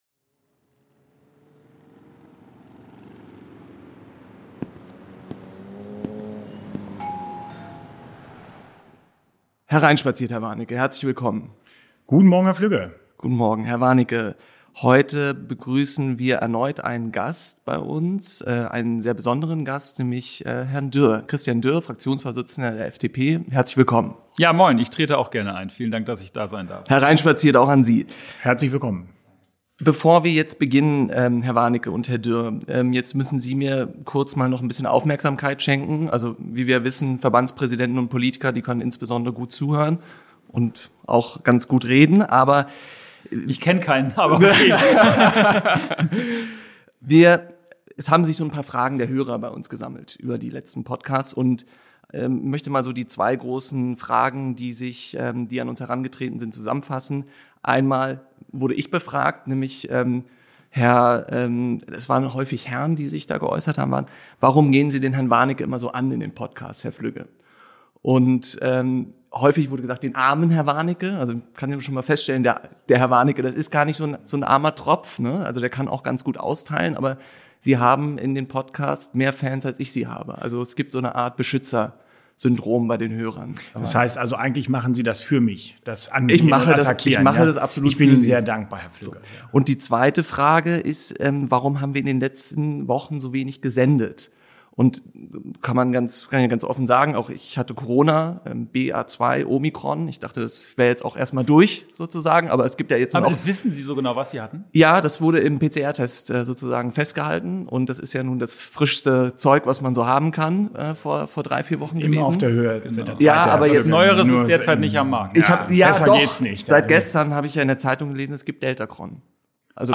Der Krieg in der Ukraine hat dazu geführt, dass wir vor großen Herausforderungen bei der Aufnahme von Flüchtlingen stehen. Aber auch aus energiepolitischer Sicht wird ein sehr kurzfristiger Paradigmenwechsel in unserem Land eingeleitet und viele ambitionierte Vorhaben aus dem Koalitionsvertrag müssen daher neu bewertet werden. Vor diesem Hintergrund haben wir in dieser Episode mit dem Fraktionschef der Freien Demokraten Christian Dürr gesprochen, sein Büro ist Dreh- und Angelpunkt der Tagespolitik der Regierung.